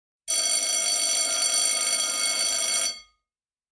Download School-bell sound effect for free.
School-bell